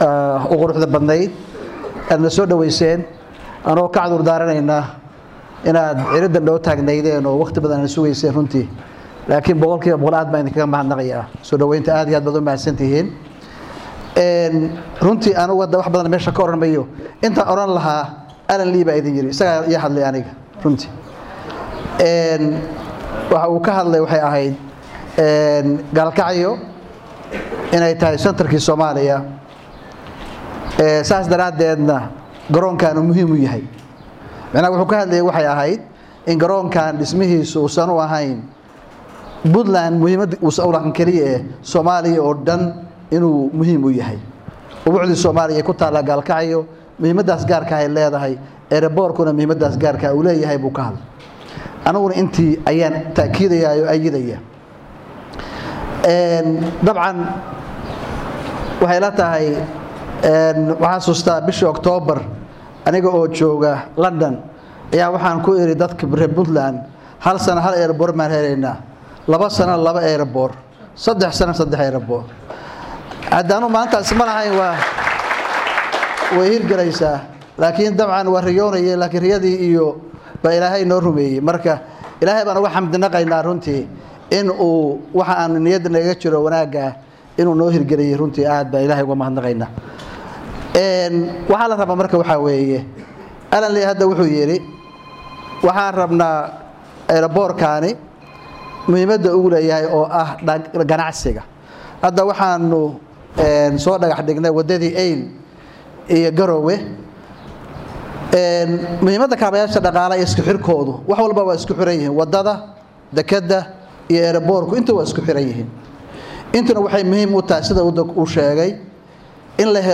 Dhagayso Khudbadii madaxweyne Gaas ee dhaxdhiga garoonka Gaalkacyo